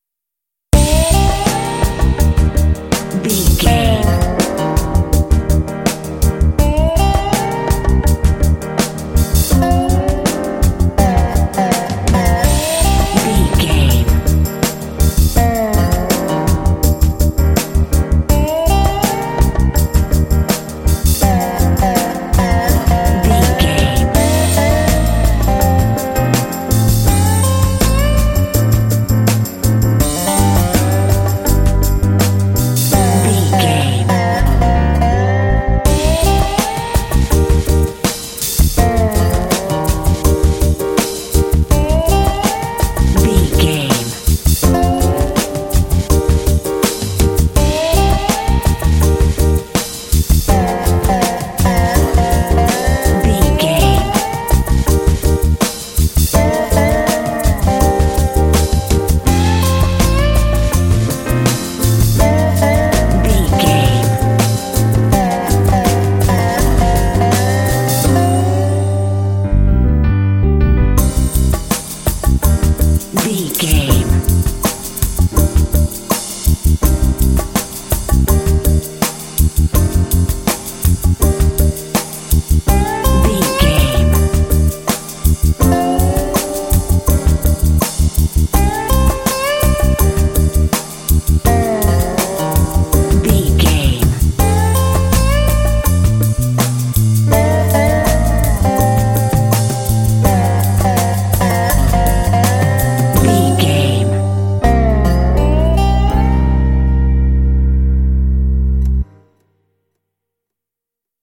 Aeolian/Minor
happy
bouncy
groovy
smooth
electric guitar
bass guitar
drums
conga
piano
americana